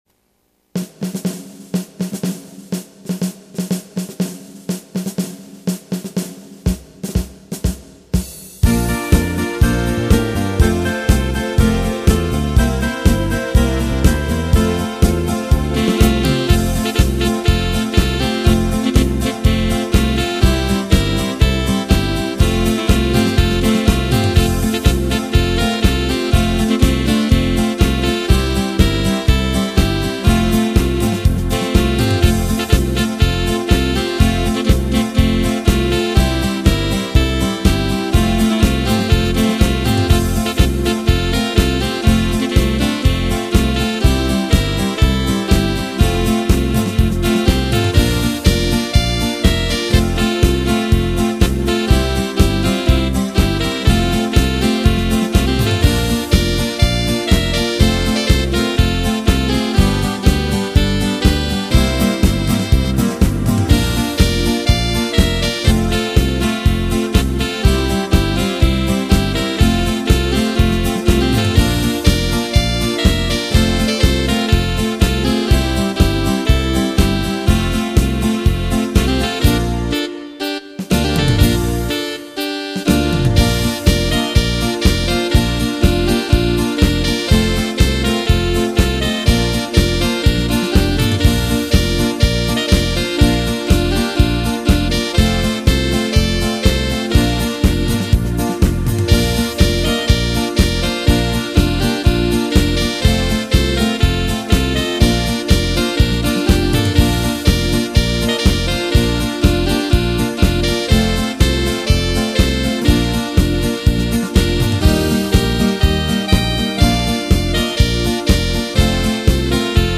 Polka , Pop